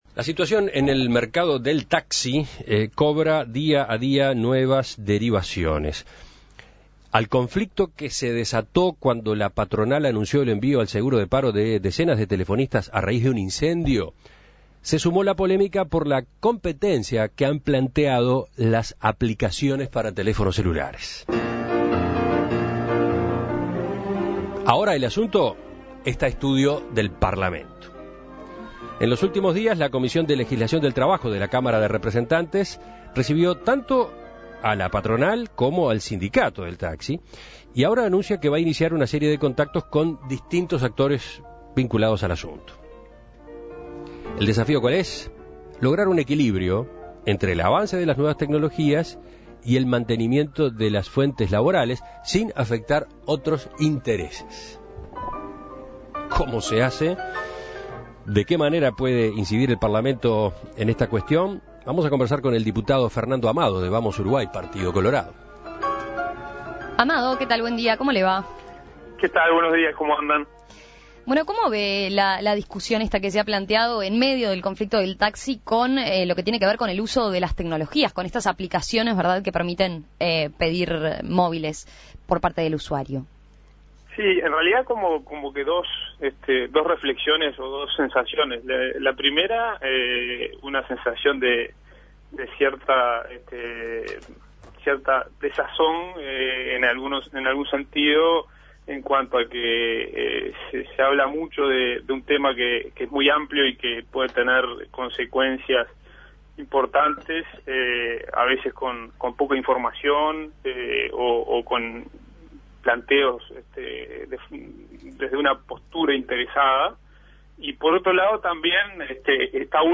La Comisión de Legislación del Trabajo de Diputados está trabajando el tema, por este motivo En Perspectiva entrevistó al diputado colorado Fernando Amado, integrante de dicha comisión.